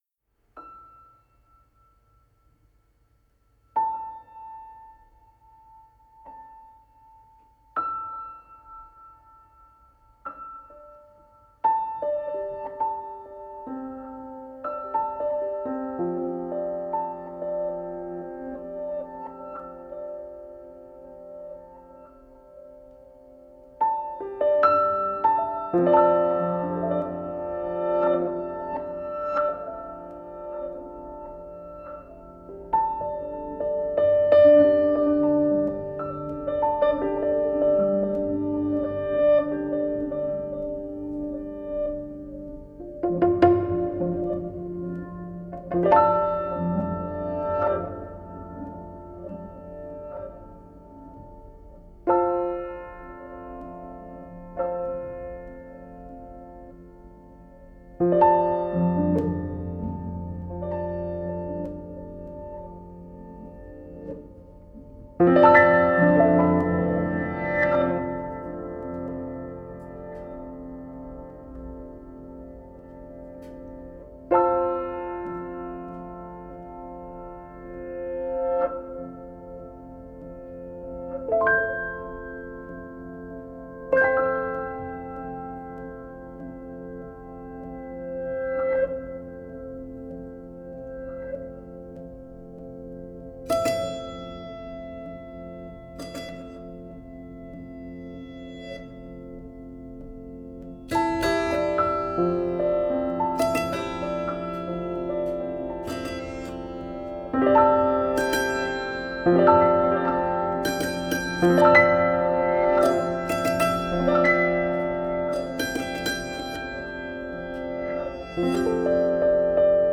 گلچینی شنیدنی از موسیقی‌های زمستانی با اجرای هنرمندان مختلف
Neoclassical, Ambient, Modern Classical